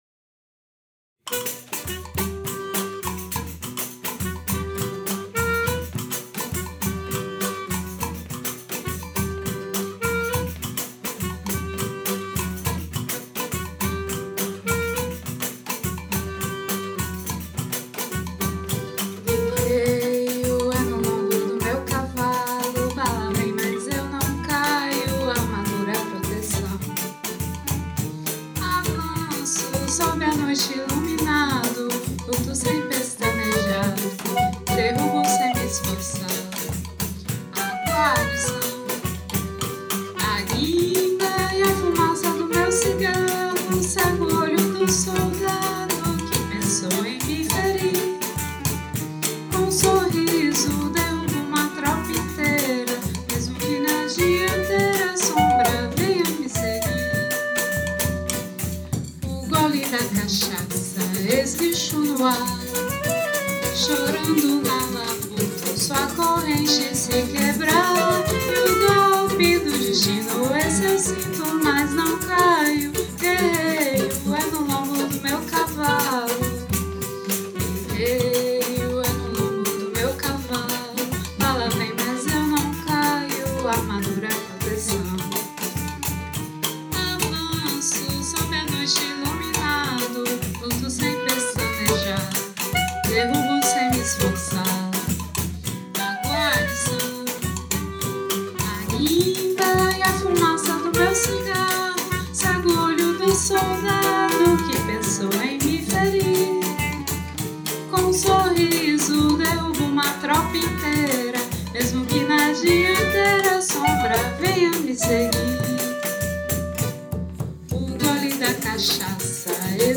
Rec atelier
en Salle Europe.